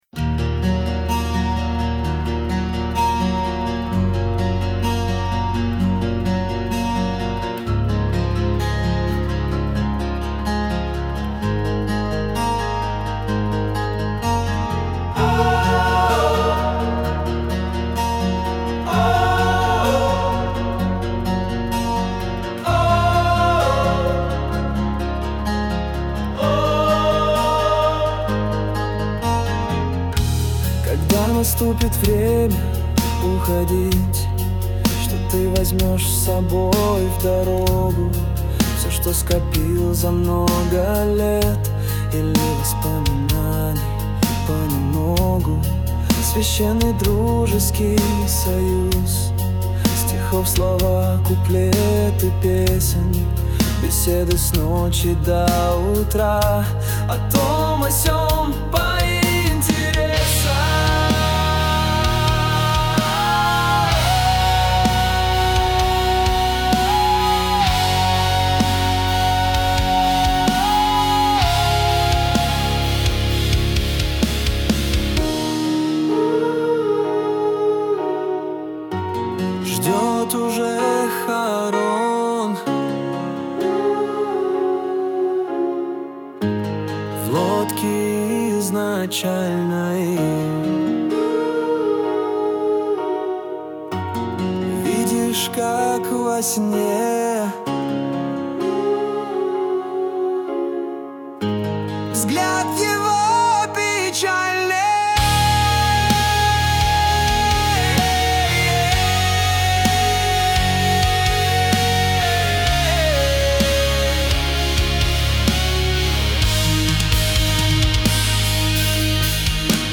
mp3,6303k] Рок